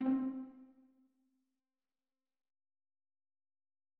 Middle C, pizzicato
• On bowed string instruments it is a method of playing by plucking the strings with the fingers, rather than using the bow.[2] This produces a very different sound from bowing, short and percussive rather than sustained.
Middle_C_pizz.mid.mp3